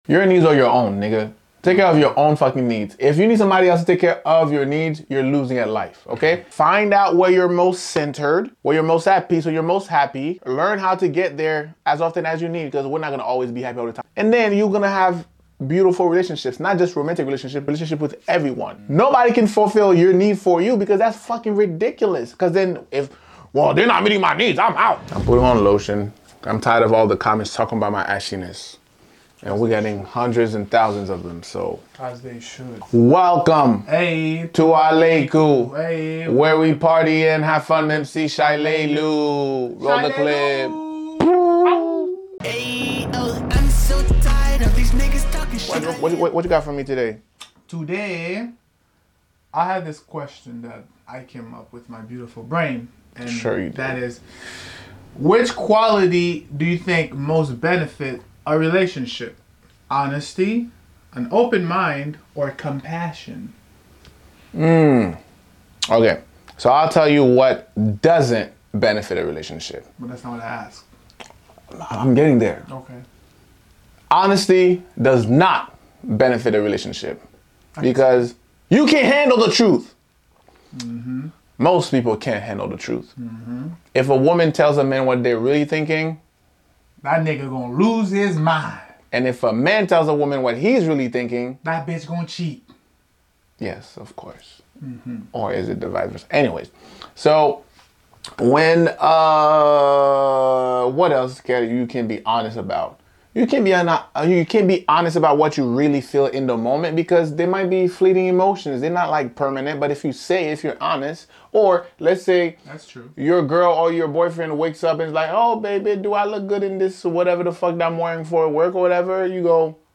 A set of argumentative twins debating topics they have no business debating.